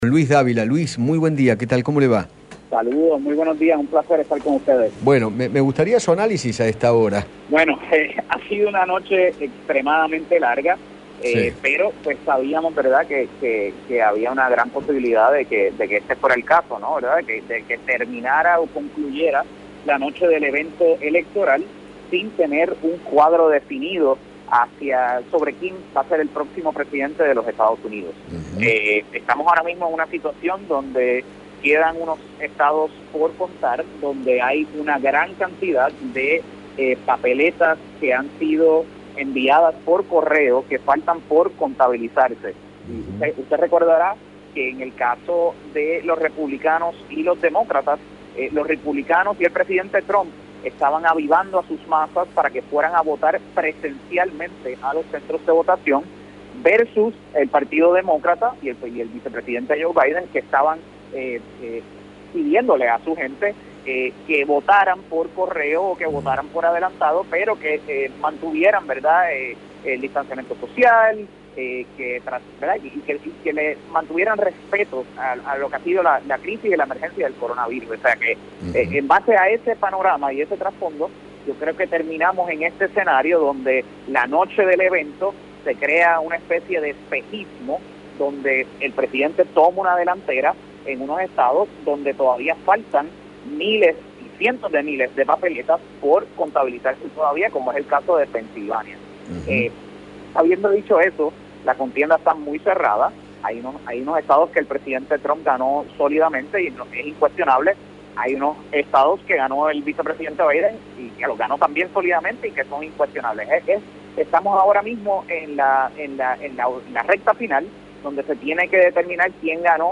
miembro del Comité Nacional del Partido Demócrata, dialogó con Eduardo  Feinmann sobre el recuento de votos de las elecciones presidenciales en Estados Unidos y aseguró que “hasta este momento